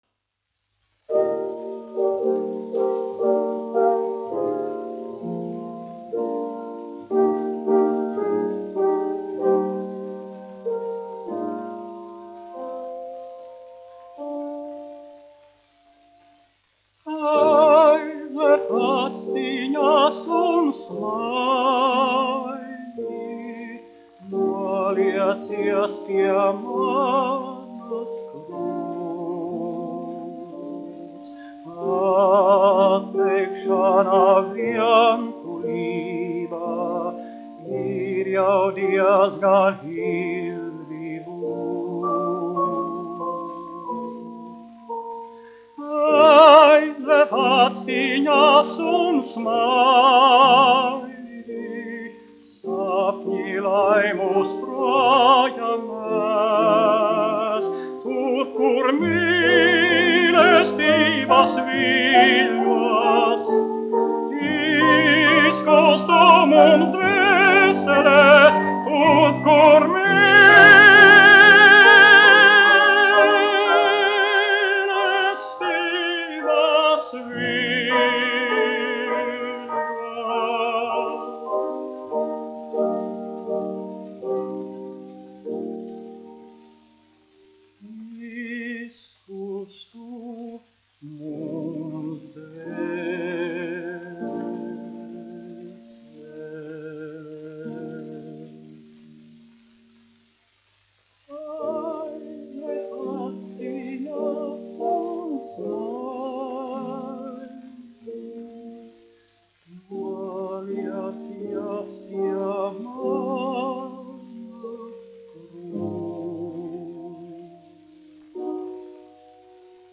1 skpl. : analogs, 78 apgr/min, mono ; 25 cm
Dziesmas (augsta balss) ar klavierēm
Skaņuplate
Latvijas vēsturiskie šellaka skaņuplašu ieraksti (Kolekcija)